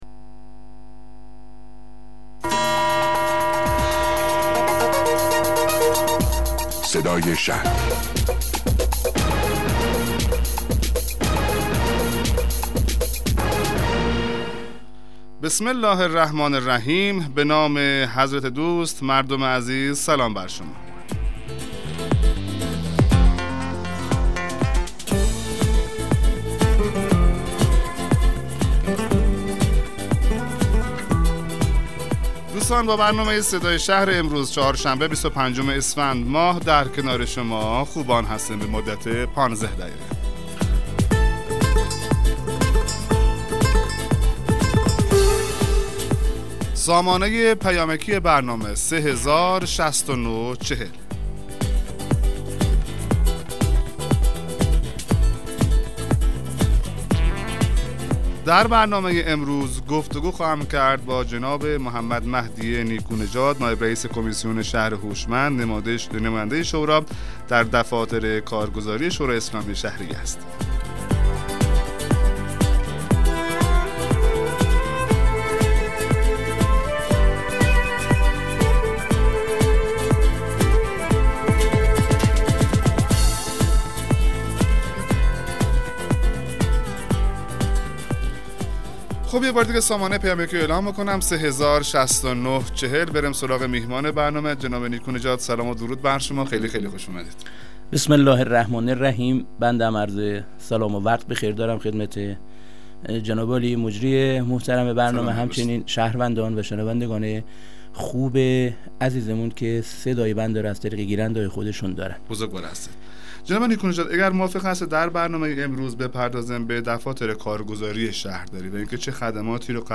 مصاحبه رادیویی برنامه صدای شهر با حضور محمدمهدی نیکونژاد عضو شورای اسلامی شهر یزد